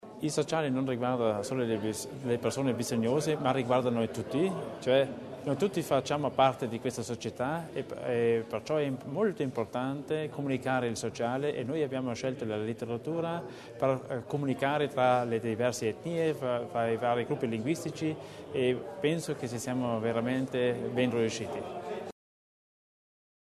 L’Assessore Theiner illustra lo scopo del progetto